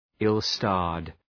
Προφορά
{,ıl’stɑ:rd} (Επίθετο) ● δύσμοιρος